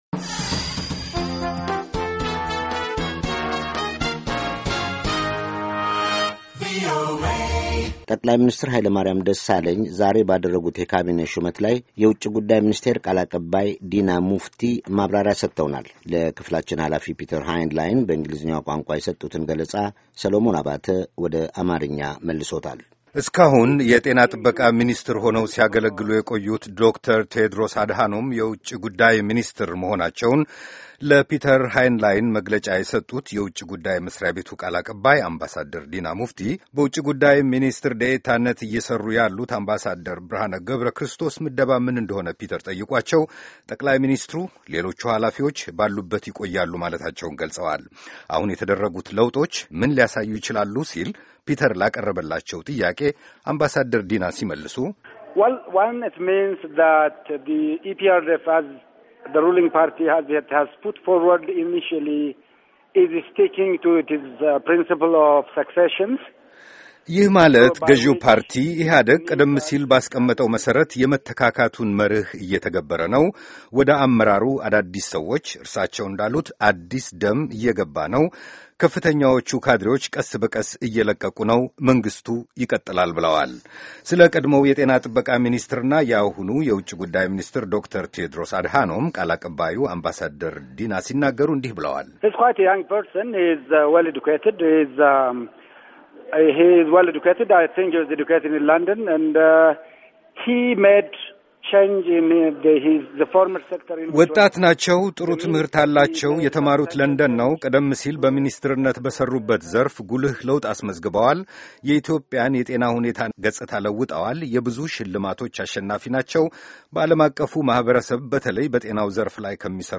በአዲሱ የካቢኔ አወቃቀርና ሹመት ላይ አምባሣደር ዲና ሙፍቲ ለቪኦኤ መግለጫ ሰጡ